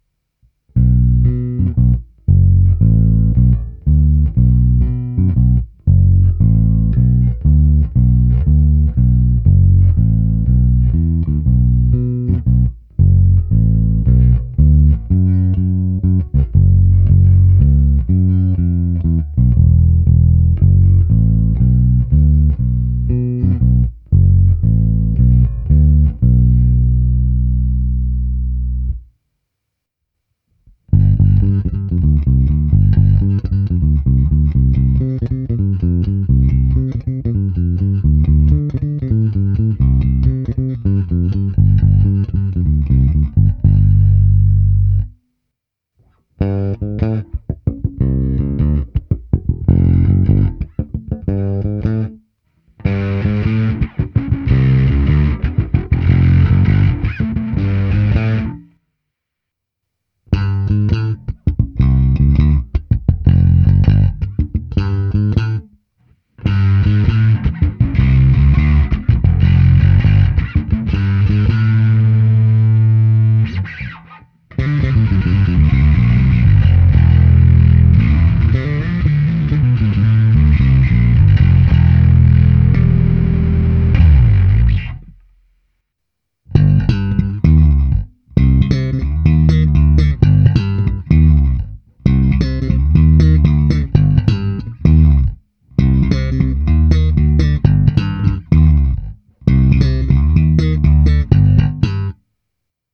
Pro tuto představu jsem prohnal Squiera skrz kompresor TC Electronic SpectraComp (recenze) a hlavně přes preamp Darkglass Alpha Omega Ultra (recenze) se zapnutou simulací aparátu. Ukázky jsou v následujícím pořadí: první část je na krkový snímač, druhá část na oba snímače, pak na kobylkový snímač a s přidáním zkreslení, pak to samé na oba snímače a s přidáním zkreslení plus neumělý Leeovský bordel na konci (odpusťte, trochu jsem se nechal unést) a nakonec slap na oba snímače.
Ukázka přes Darkglass